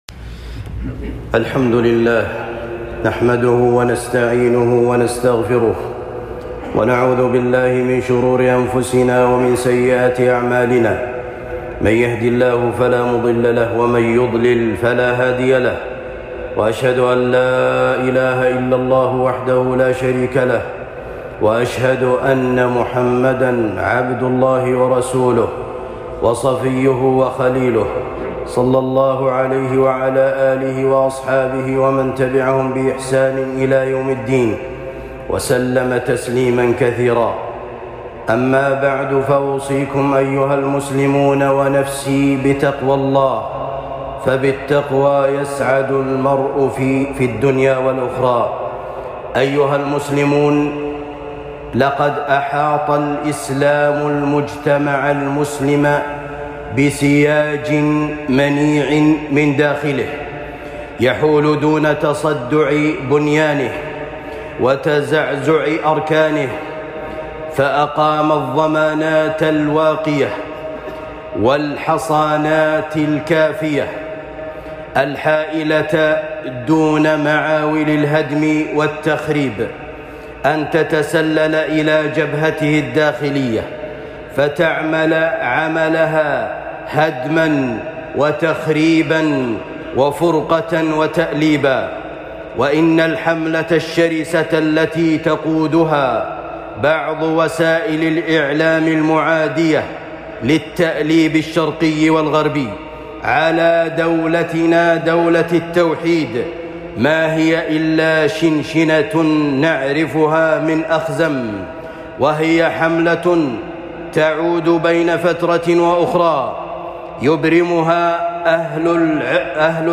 نعمٌ عظيمة تنعم بها بلادنا الكريمة خطبة جمعة